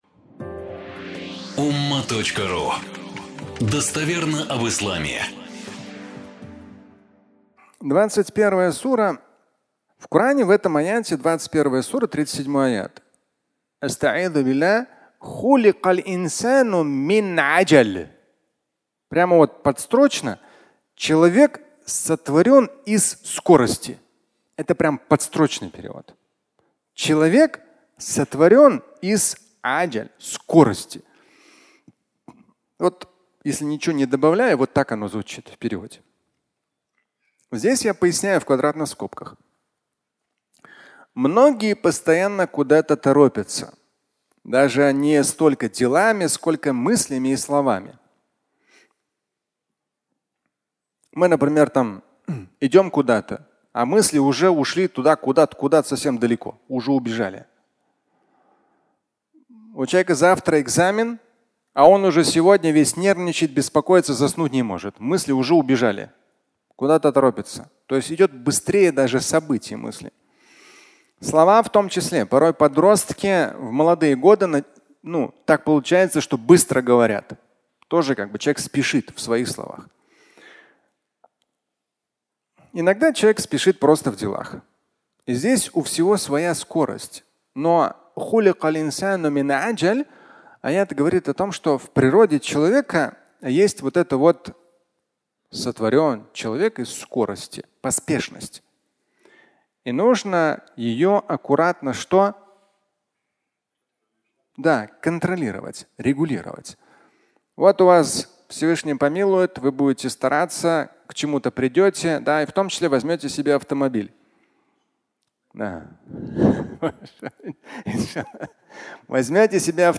Скорость (аудиолекция)